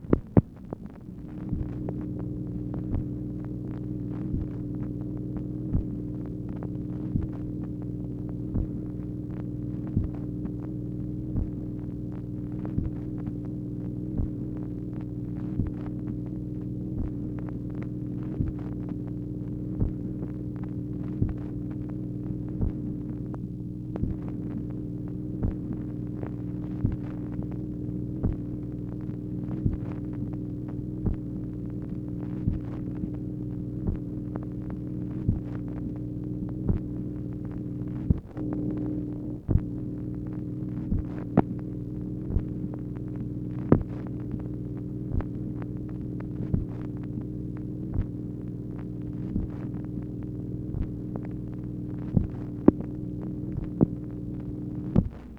MACHINE NOISE, January 11, 1964
Secret White House Tapes | Lyndon B. Johnson Presidency